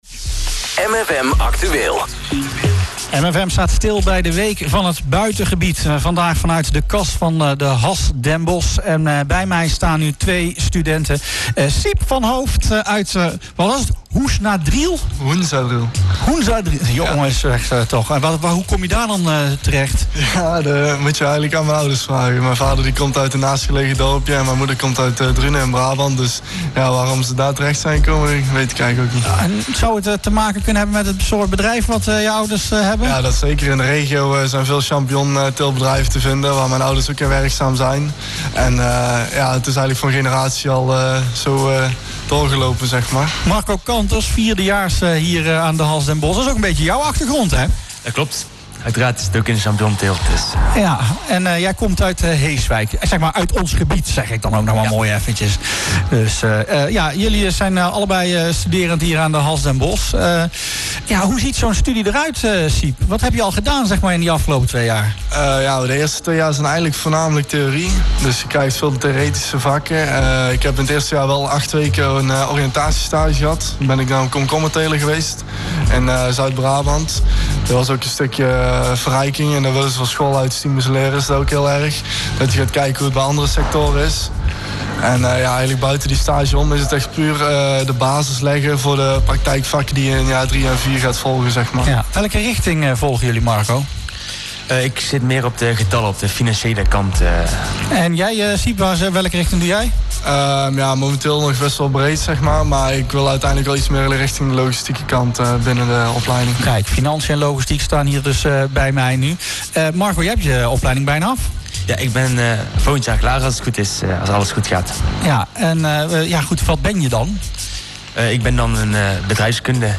De week werd vrijdag (26 oktober) afgesloten op de HAS Den Bosch. Daar stond de innovatie van de landbouwsector centraal.